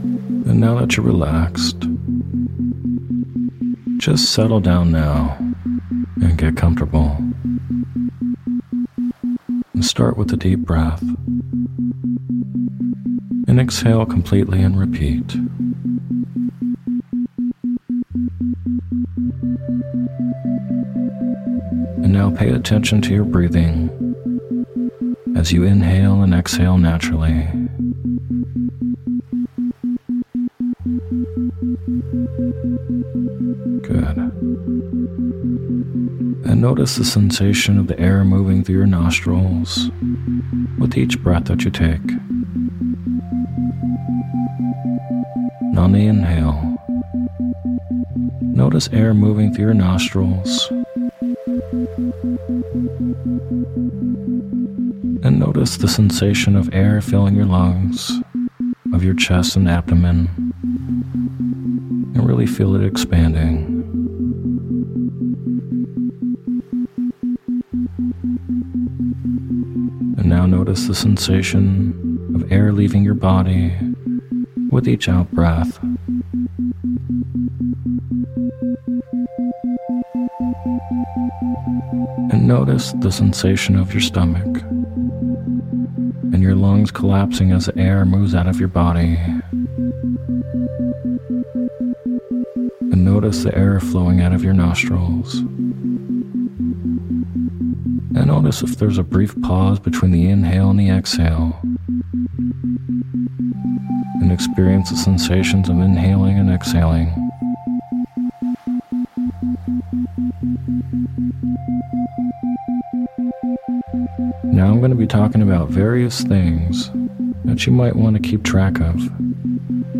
Sleep Hypnosis For Improving Your Meditation Skills and Sessions With Isochronic Tones
In this guided meditation, or hypnosis audio we’ll do a visualization to help improve your meditation abilities and skills.
Improving-Meditation-ISO-Sample.mp3